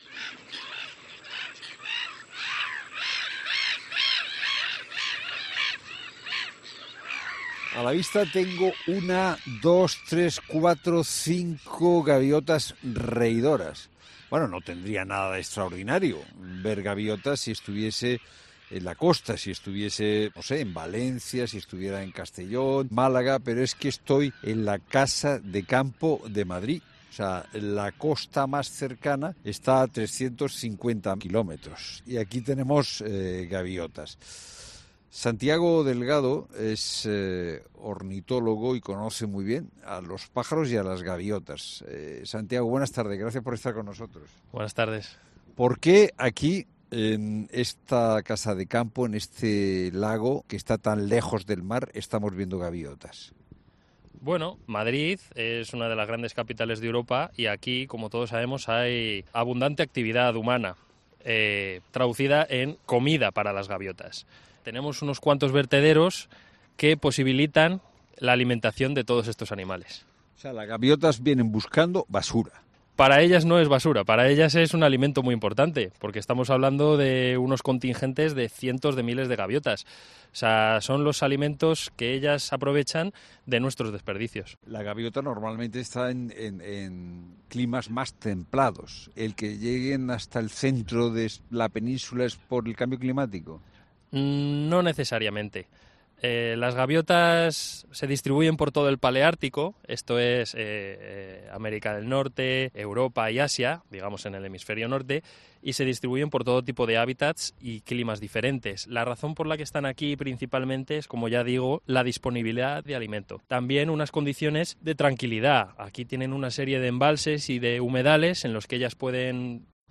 El avistamiento de gaviotas lejos de la costa, en Madrid, puede llamar la atención de muchos ciudadanos y curiosos, y por ello en 'La Tarde' un ornitólogo explica por qué están ahí